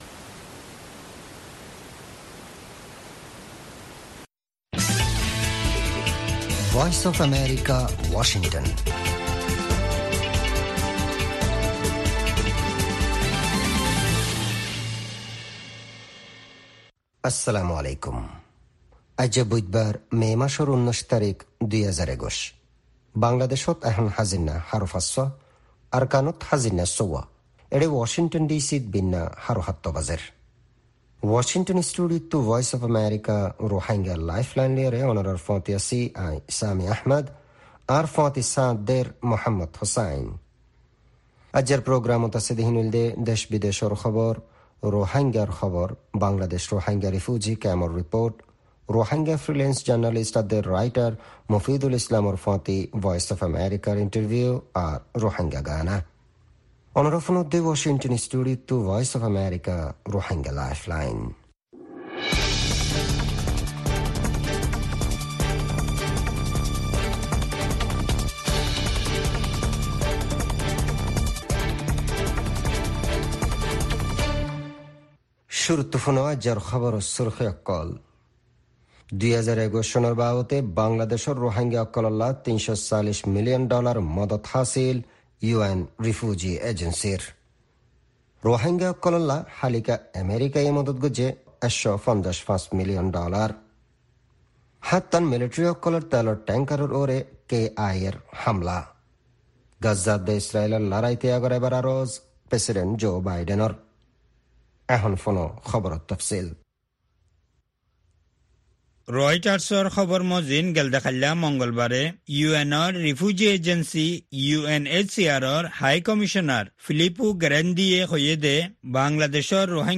Rohingya Broadcast